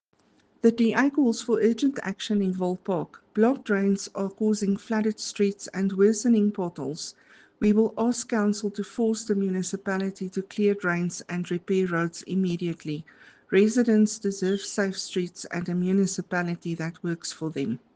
Afrikaans soundbites by Cllr Ruanda Meyer and